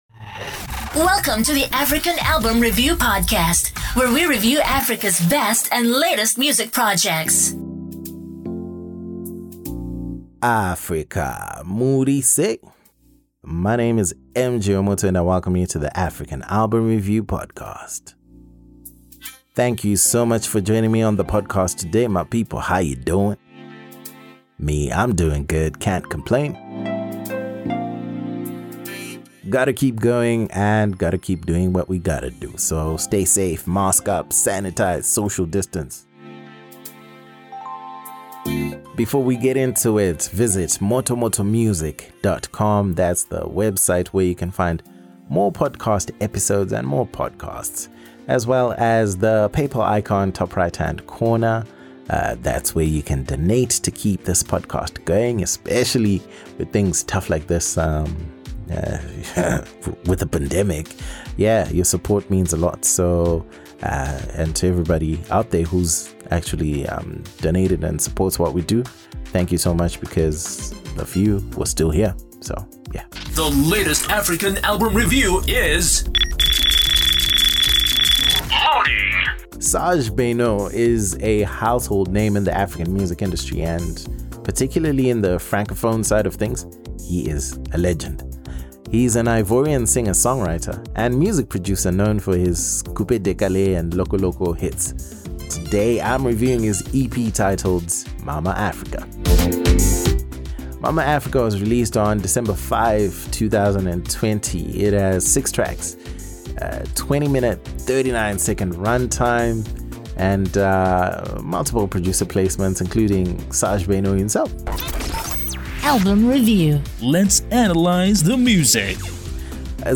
Music commentary and analysis on African albums